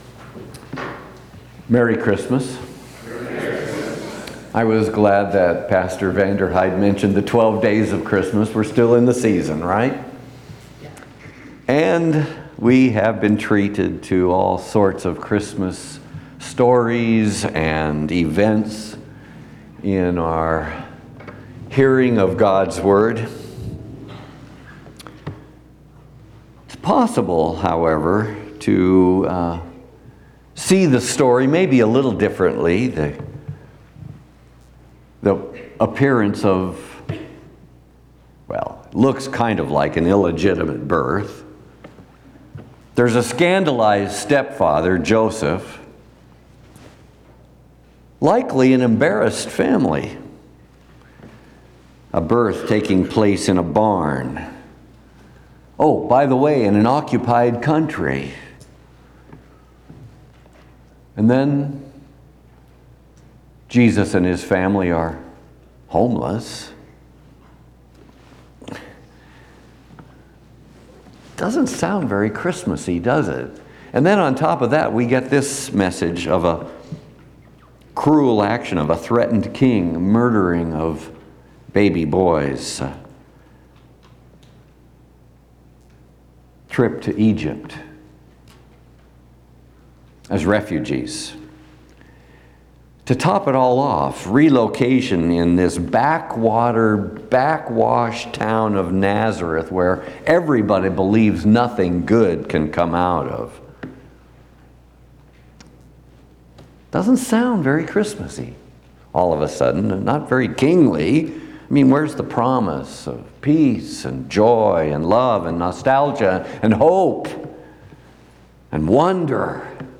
First Sunday after Christmas&nbsp
Trinity Lutheran Church, Greeley, Colorado Christmas and Suffering Dec 28 2025 | 00:17:49 1x 00:00 / 00:17:49 Subscribe Share RSS Feed Share Link Embed